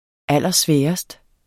Udtale [ ˈalˀʌˈsvεːʌsd ]